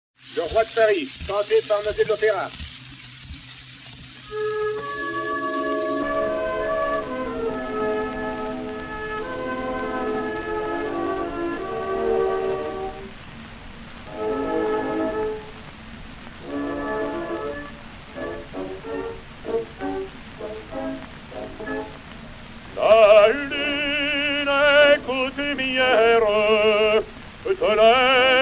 Jean Noté (Longnac, créateur) et Orchestre
Pathé saphir 90t P 118-2, réédité sur 80t P 86, enr. en 1910